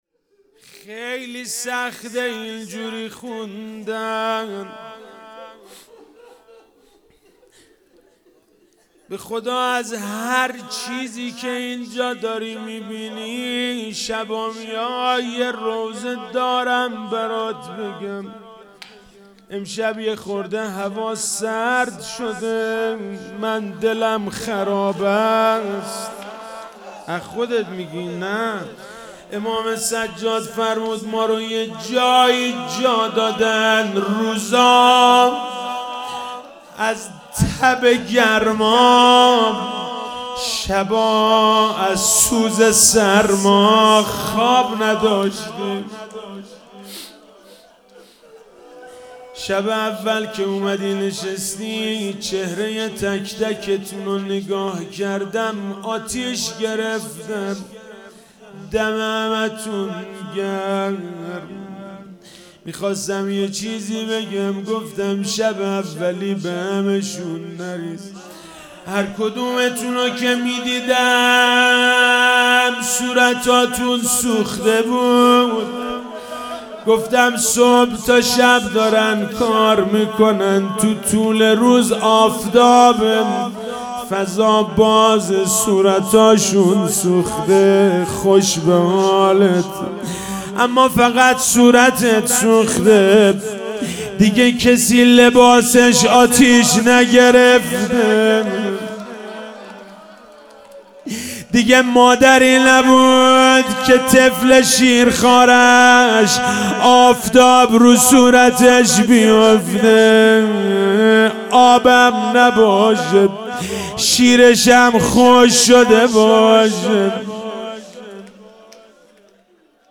شب پنجم محرم 1399
4- روضه - امام سجاد فرمود مارو يه جايي جادادن